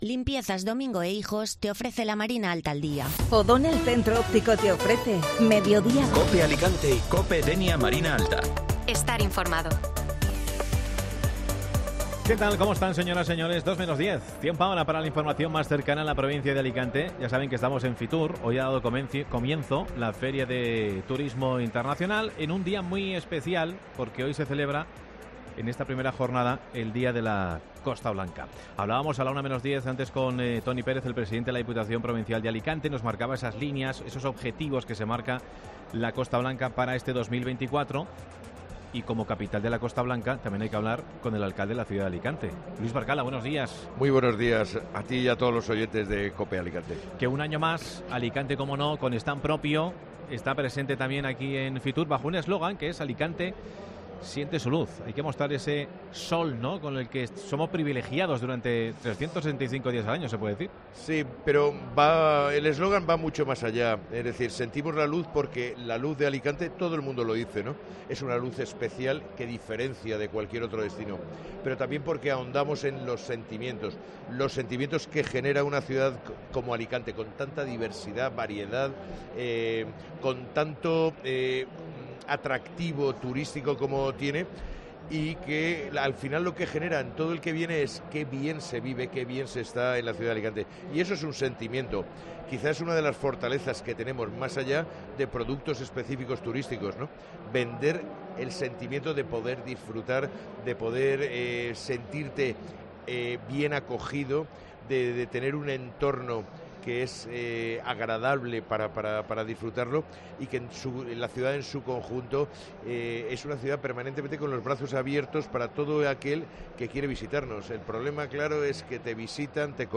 ESPECIAL FITUR 2024 "Sostenibilidad, accesibilidad y digitalización, queremos presentar una ciudad para todos" Entrevista con Luís Barcala, Alcalde de Alicante